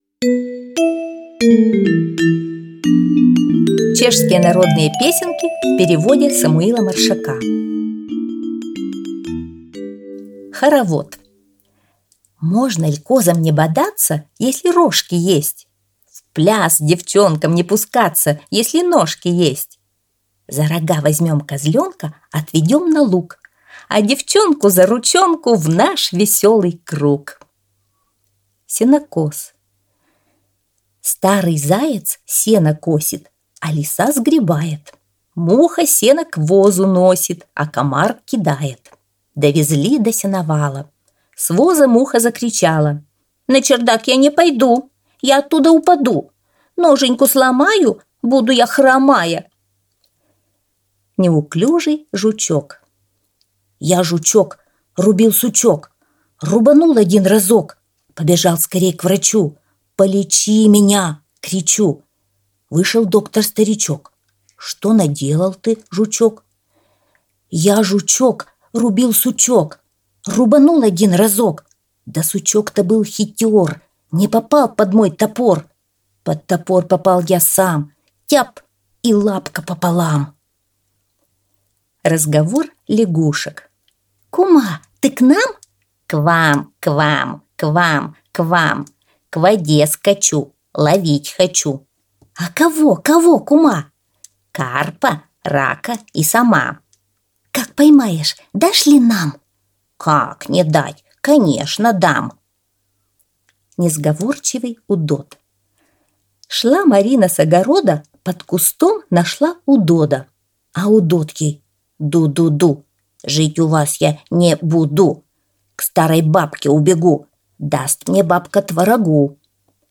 Аудио стих «Чешские народные песенки»